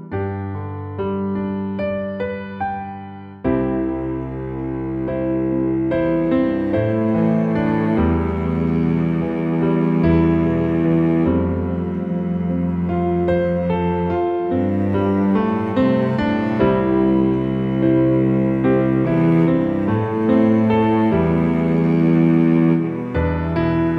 -2 Male Key